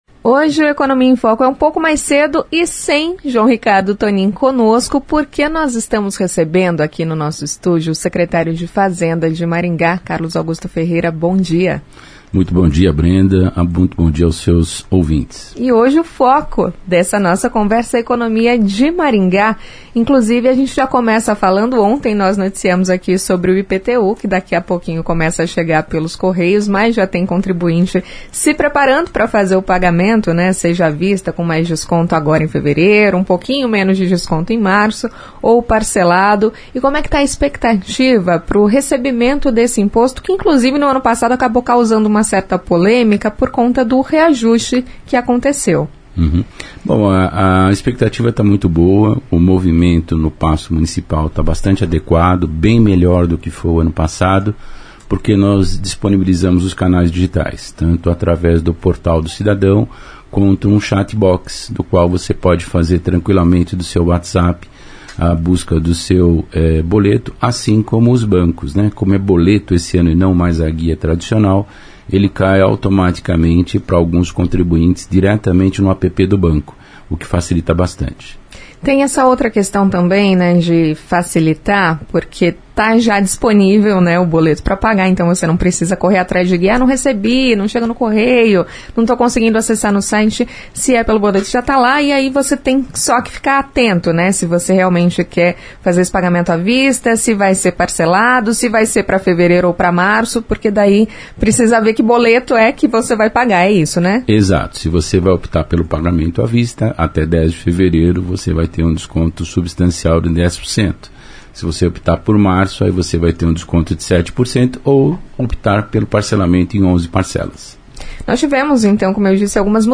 Carlos Augusto Ferreira, em entrevista à CBN Maringá, disse que os canais digitais otimizaram a emissão das guias.